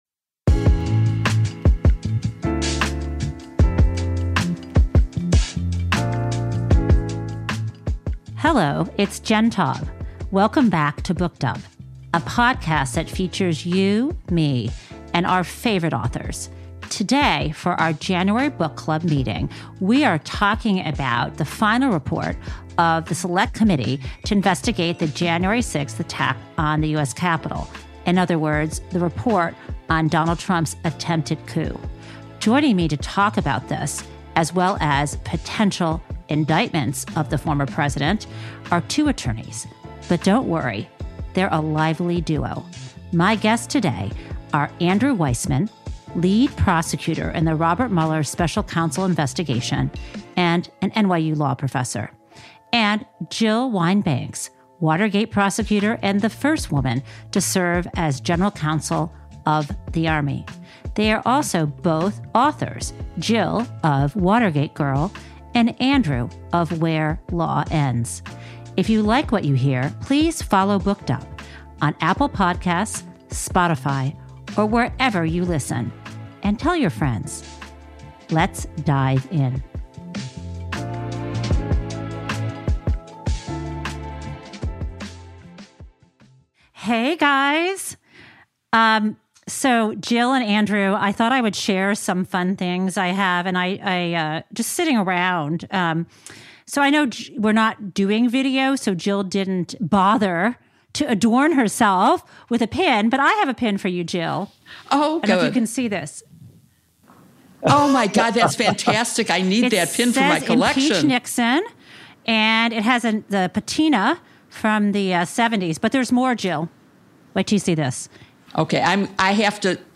We have two special guests.